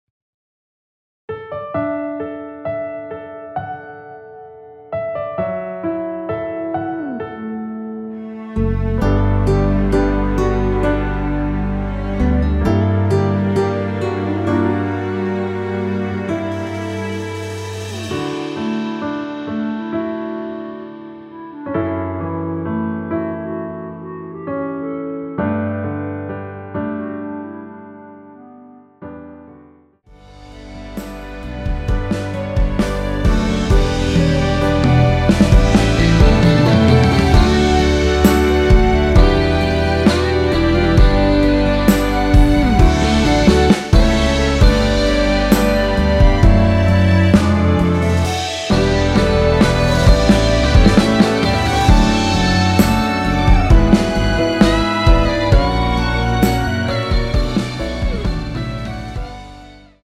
원키에서(-1)내린 멜로디 포함된 MR입니다.(미리듣기 확인)
앞부분30초, 뒷부분30초씩 편집해서 올려 드리고 있습니다.
중간에 음이 끈어지고 다시 나오는 이유는